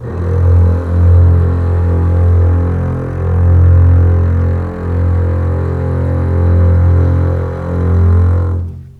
F1 LEG MF  R.wav